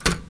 Index of /90_sSampleCDs/Roland - Rhythm Section/PRC_FX Perc 1/PRC_Typewriter